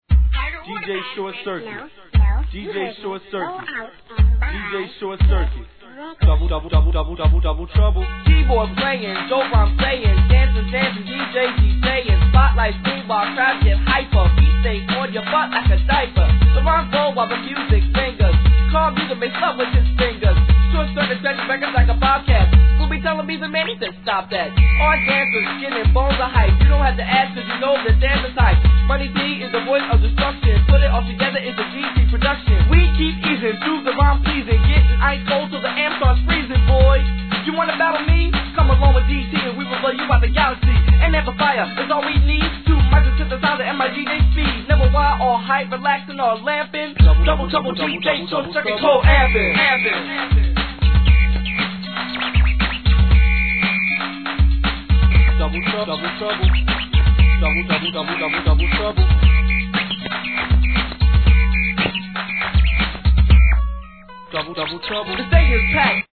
1989年、海外でもWANTの多いレア･エレクトロHIP HOP!!